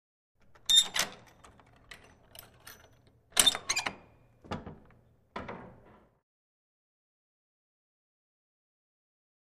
Metal Latch Clicks 2.